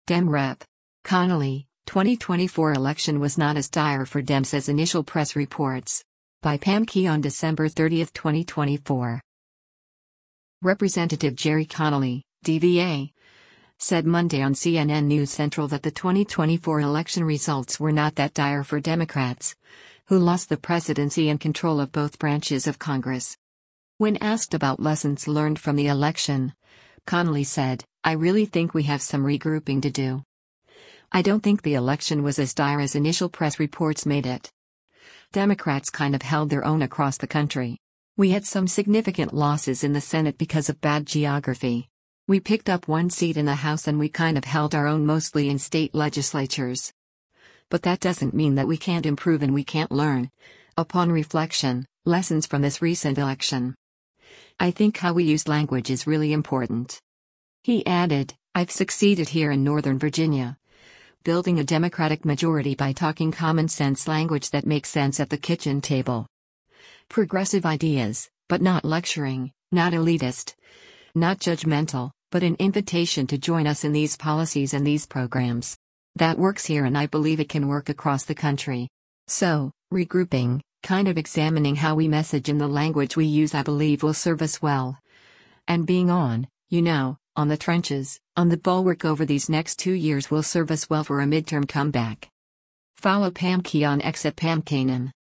Representative Gerry Connolly (D-VA) said Monday on CNN News Central that the 2024 election results were not that dire for Democrats, who lost the presidency and control of both branches of Congress.